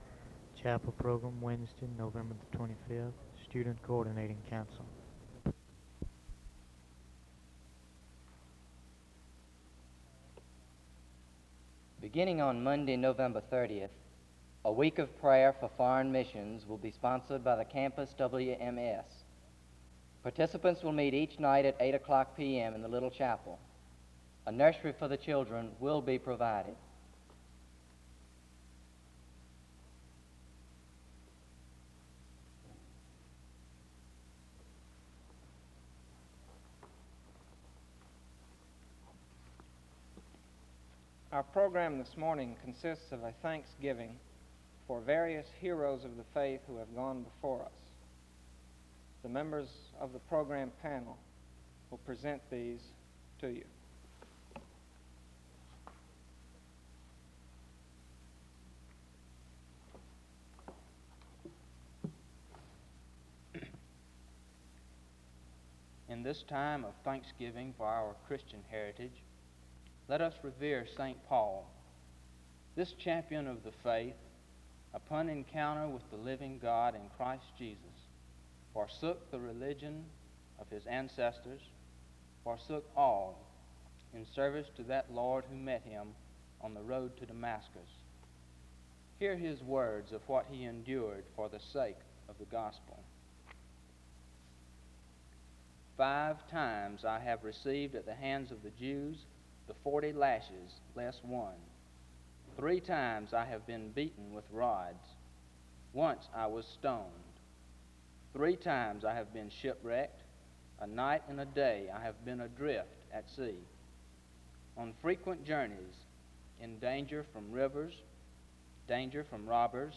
Download .mp3 Description The service begins with announcements and a segment of celebrating the apostle Paul from 0:00-3:37.
A hymn plays from 7:18-10:09. Martin Luther is celebrated from 10:17-11:30.
Hebrews 11:32-12:2 is read from 21:05-23:30. Music plays from 23:48-26:57.